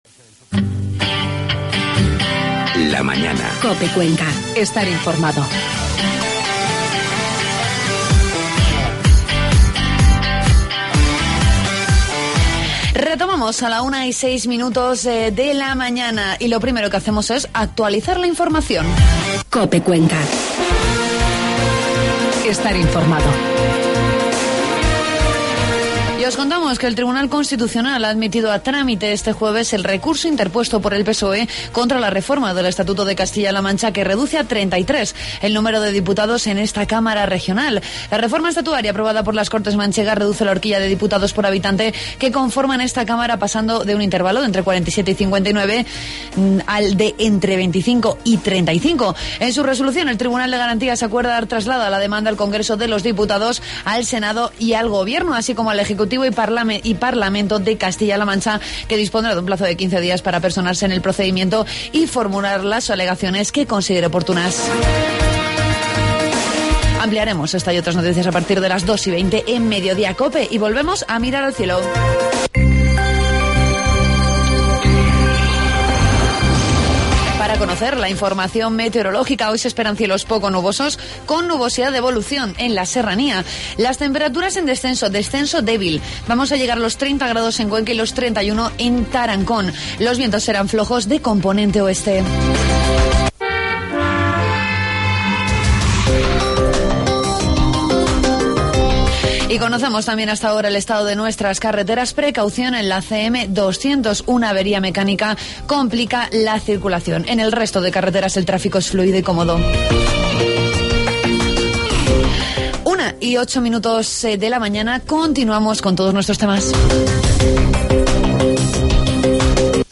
Escuchamos también la opinión de nuestros tertulianos sobre diversos asuntos de actualidad.